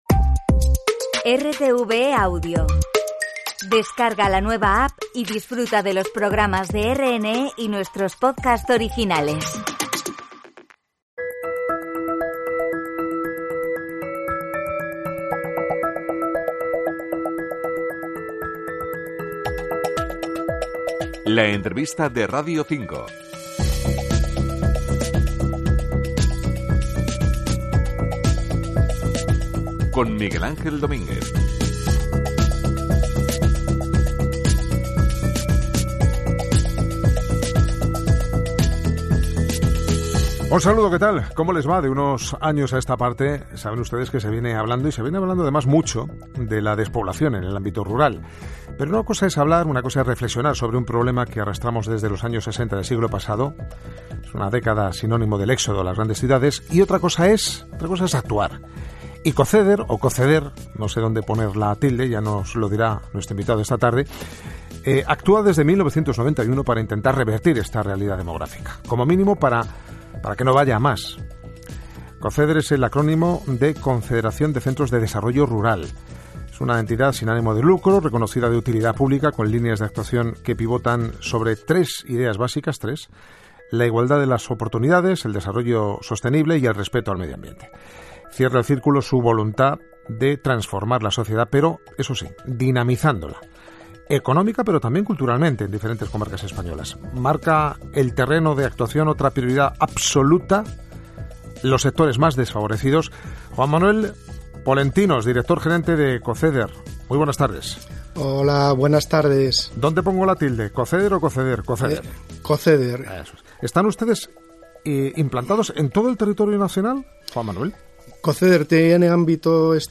La entrevista de Radio 5